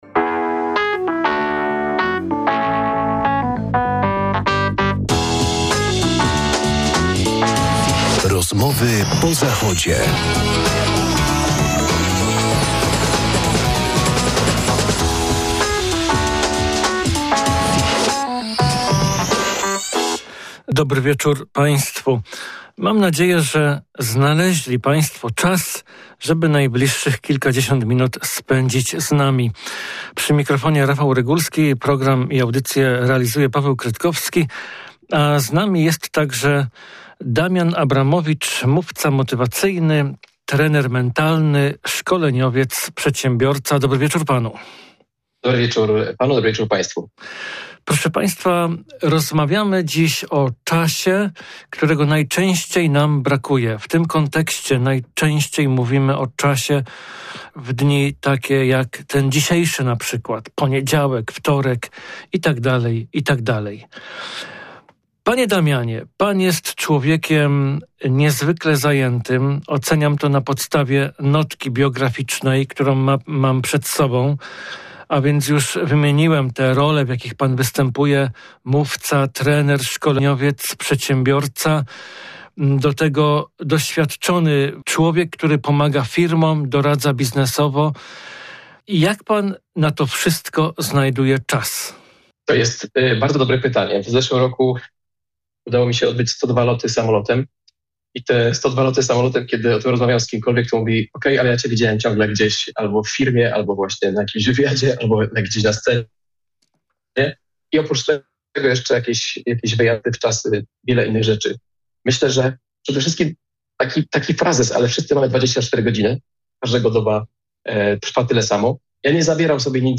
Jednym ze złodziei naszego czasu jest internet, ale tylko dlatego, że mu na to pozwalamy. Jeśli nam na kimś i na czymś zależy, to czas dla tego kogoś i dla tego czegoś znajdziemy zawsze. Polecamy rozmowę o tzw. "braku czasu".